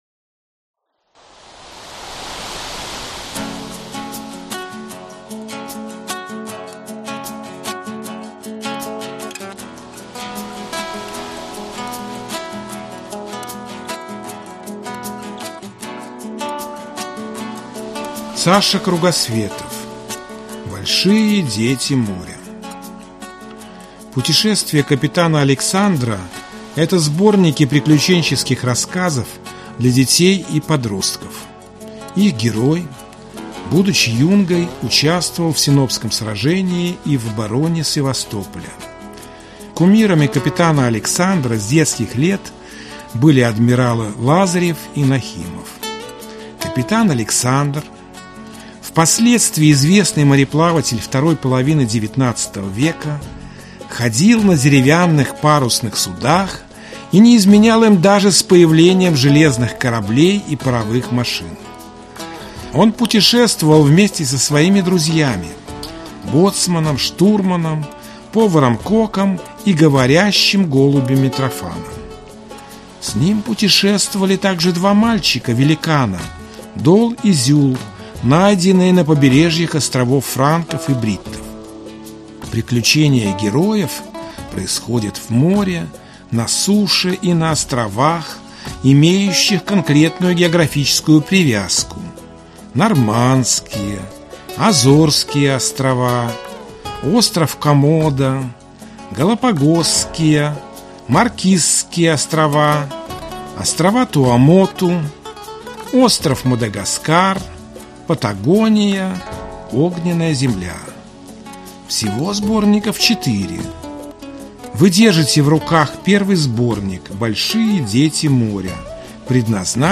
Аудиокнига Большие дети моря | Библиотека аудиокниг